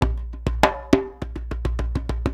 100 JEMBE2.wav